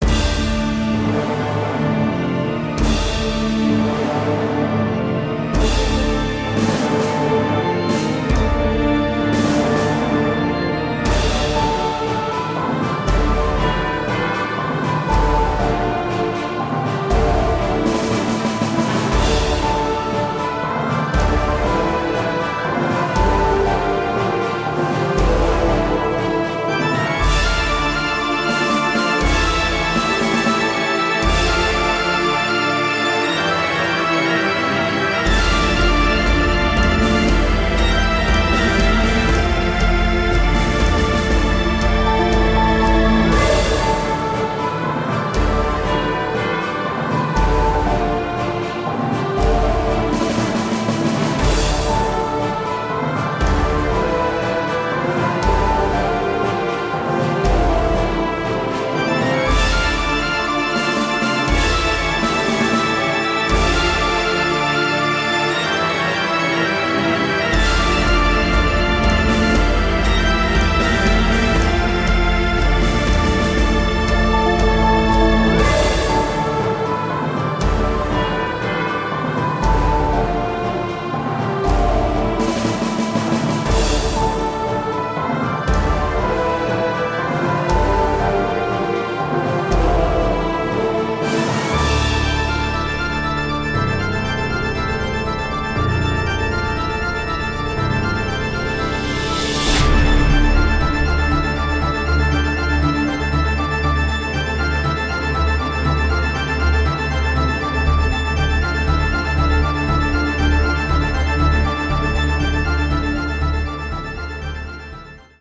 Remix / Medley
Ist also noch Work in Progress